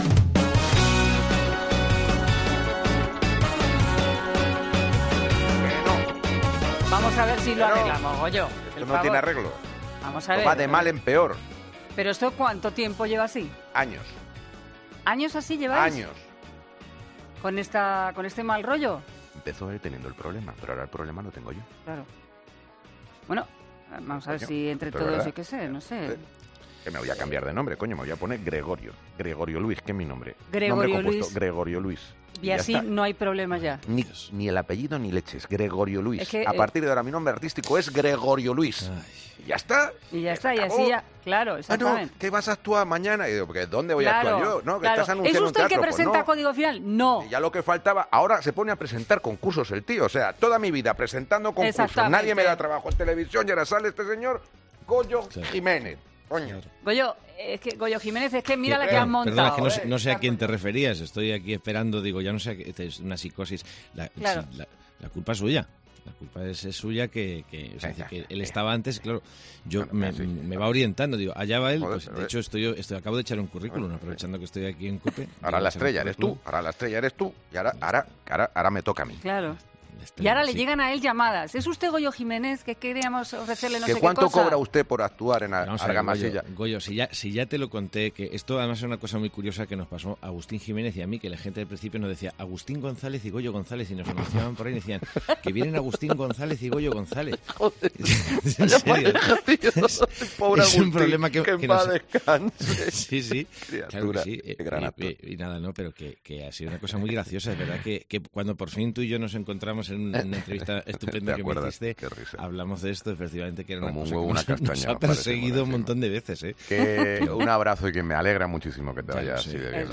AUDIO: Entrevistamos al humorista Goyo Jiménez